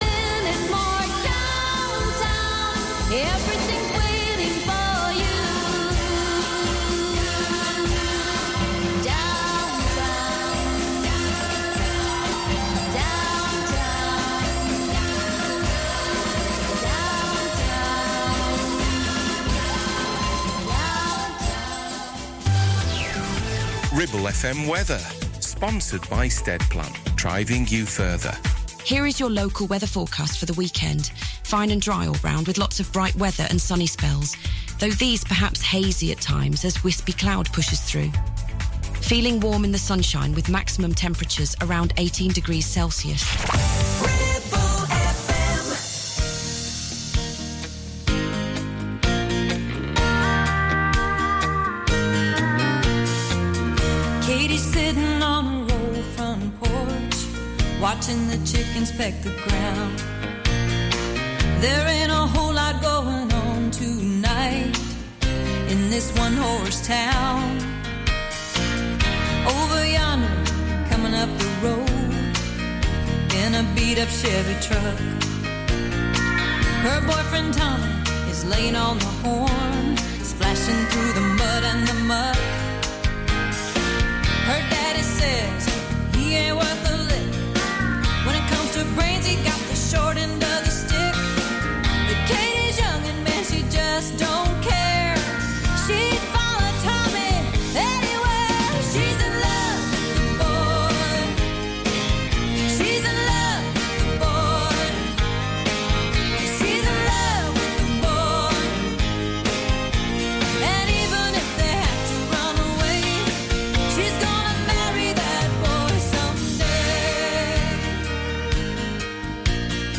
Some of the finest music from the country scene, modern and classics all together